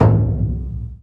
Index of /90_sSampleCDs/Roland LCDP14 Africa VOL-2/PRC_Burundi Drms/PRC_Burundi Drms